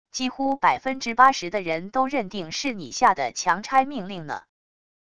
几乎百分之八十的人都认定是你下的强拆命令呢wav音频生成系统WAV Audio Player